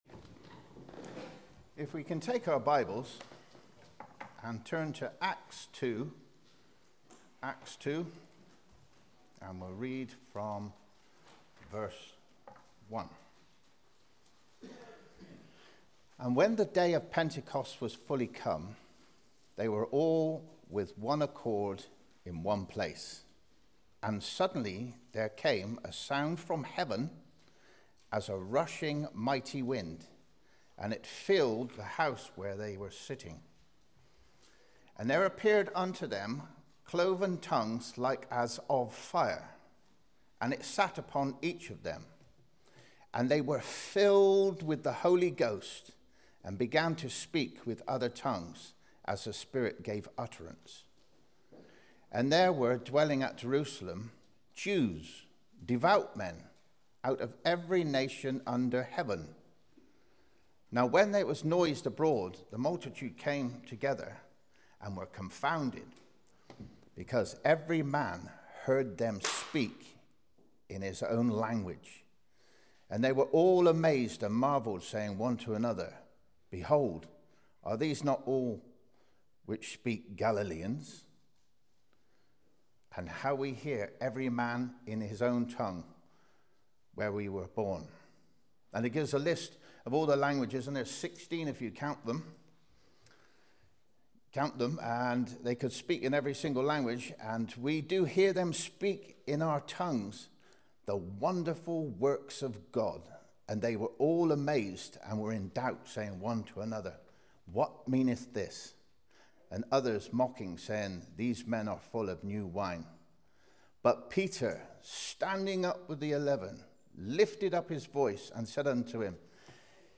The Gospel in Acts 2 (40 mins) – Hebron Gospel Hall